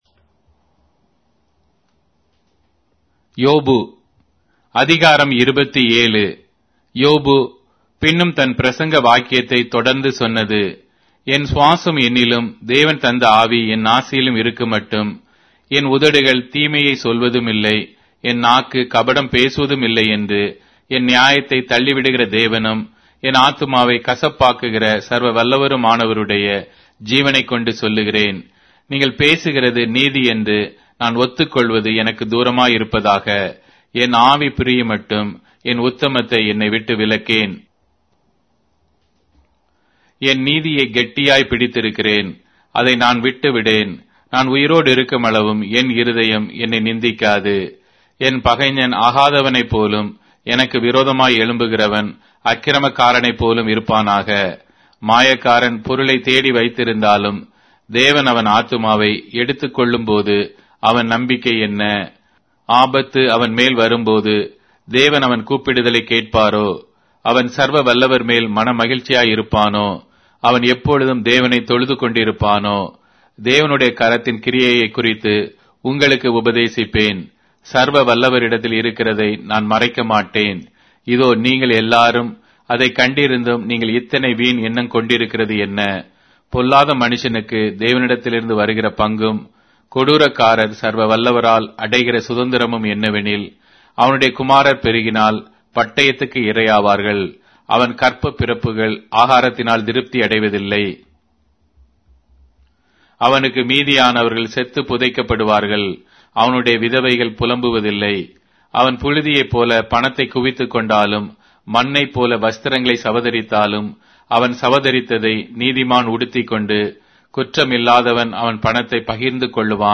Tamil Audio Bible - Job 37 in Kjv bible version